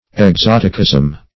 Exoticism \Ex*ot"i*cism\, n.